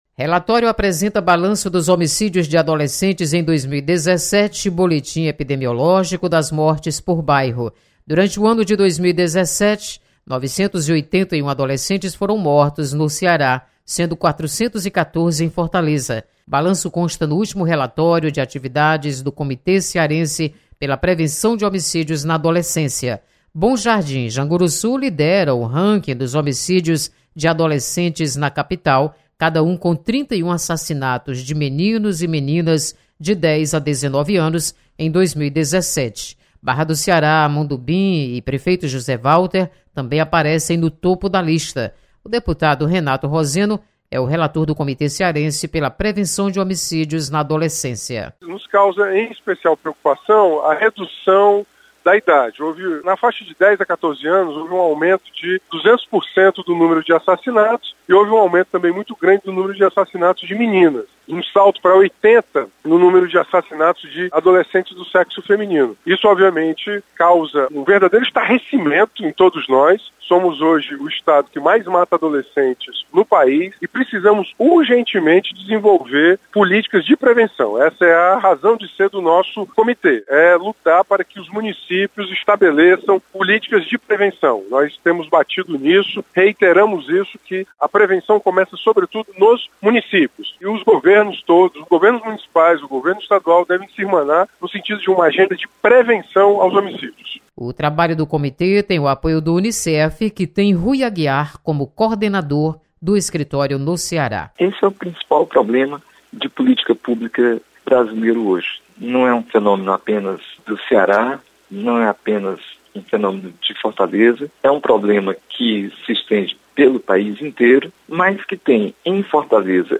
Deputado Renato Roseno destaca dados apresentados pelo Comitê Cearense de Prevenção de Homicídios na Adolescência.